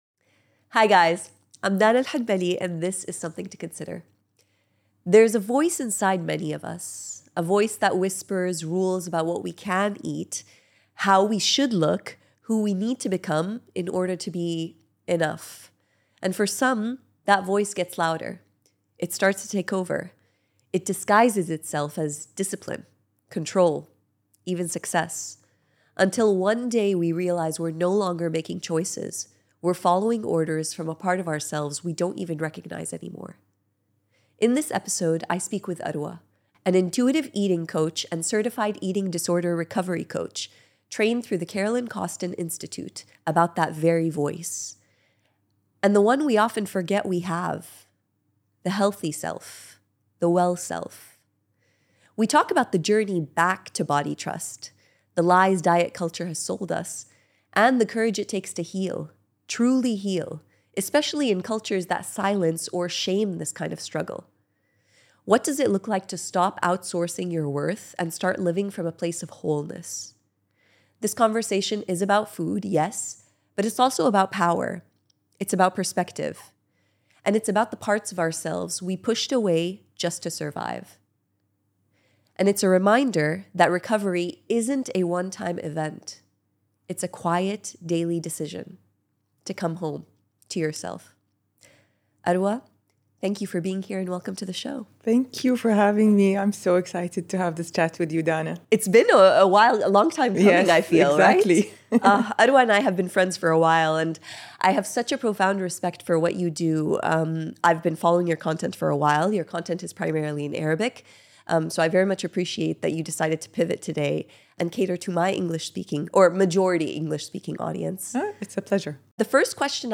A special conversation about what it takes to make partnership work on all fronts.